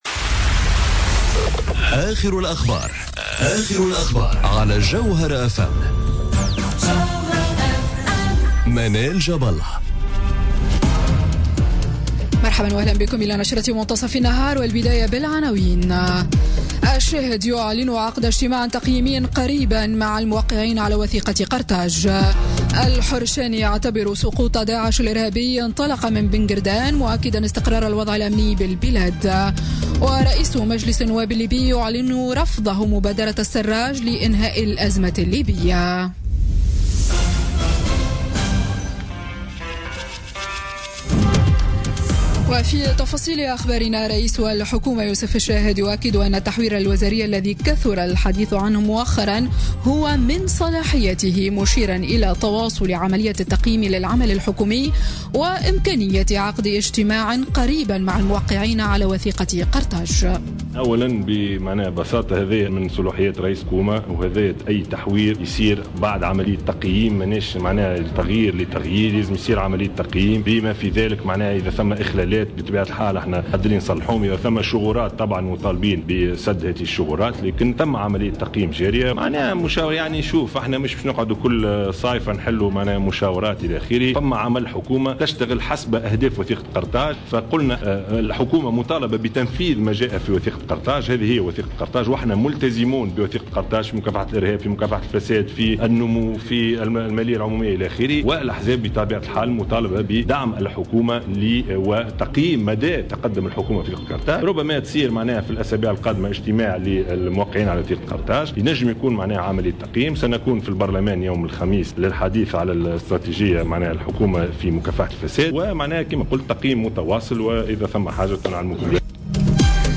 نشرة أخبار منتصف النهار ليوم الثلاثاء 18 جويلية 2017